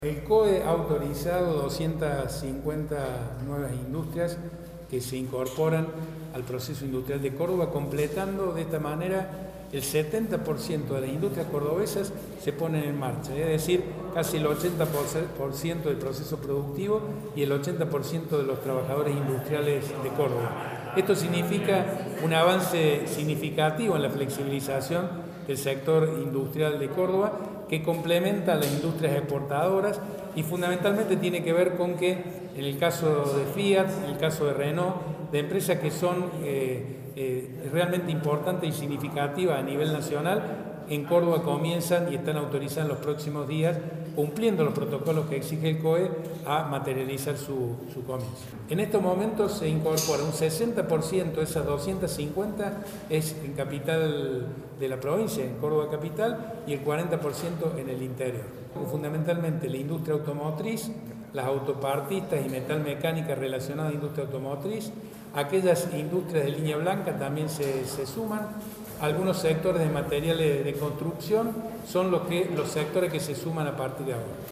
Audio: Eduardo Accastello (Ministro de Industria, Comercio y Minería – Córdoba).